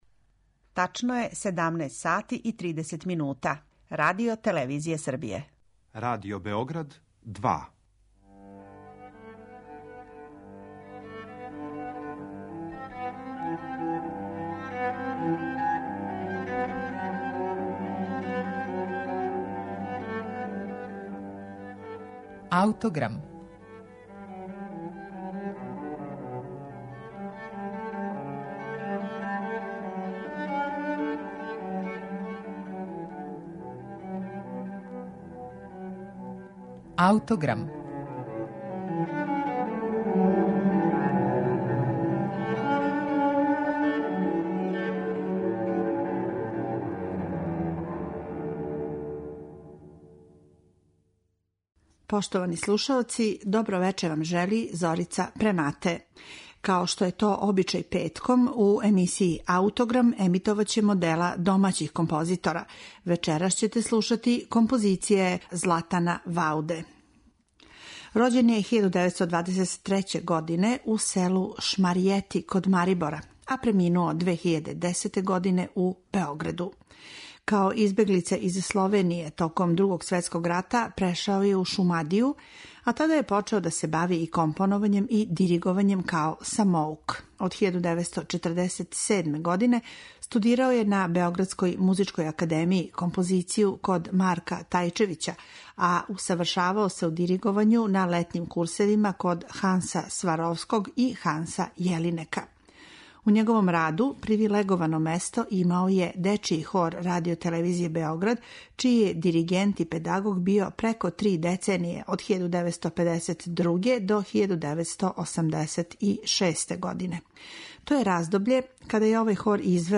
а слушате га са архивског снимка из 1960. године.